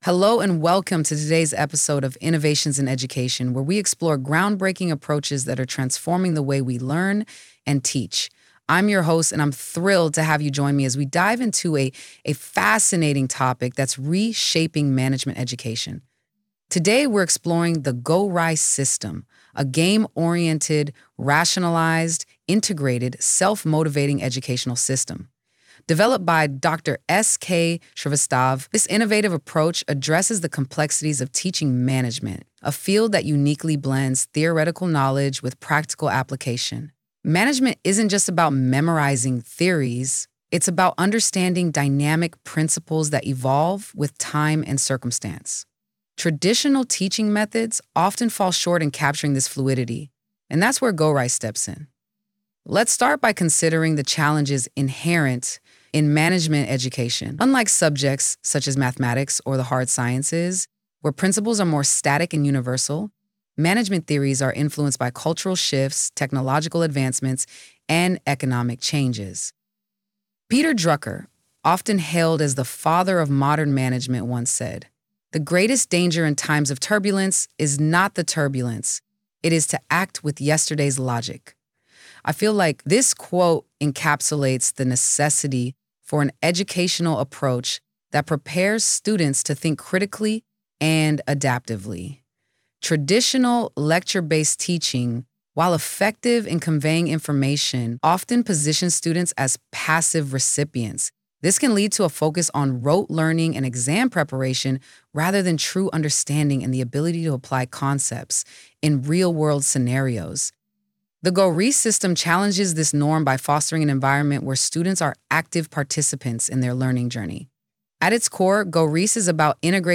GORISE playai lecture ( A ).wav